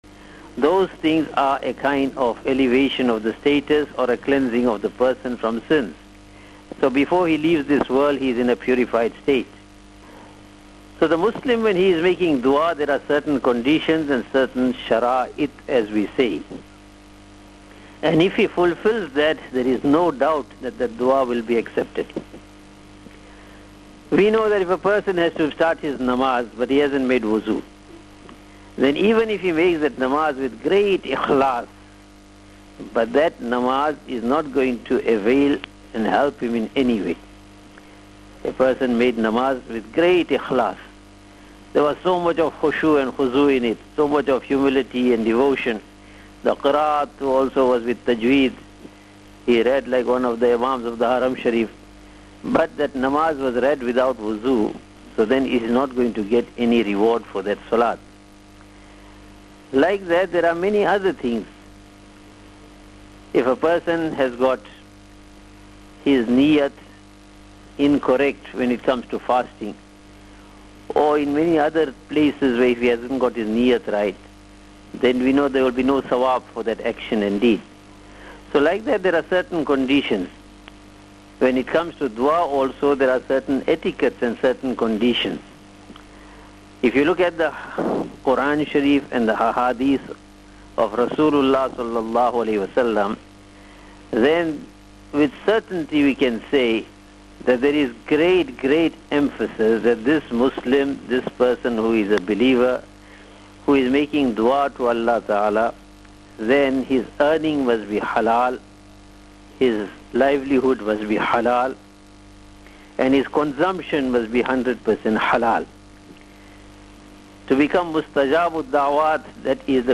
HOW TO MAKE DU'A AND SPEND THE NIGHT IN IBAADAT ON LAYLATUL BARA'AH - LIVE ON Cii - SABAHUL KHAIR